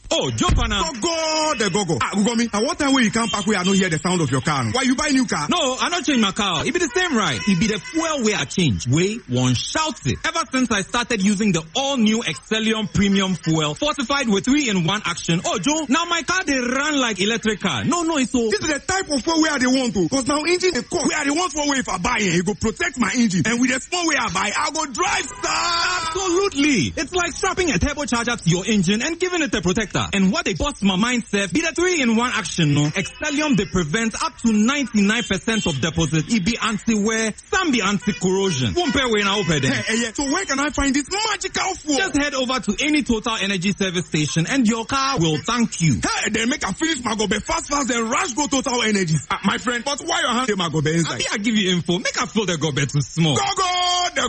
Station: Citi FM
Brand: Excellium Advert: Premium Engine Oil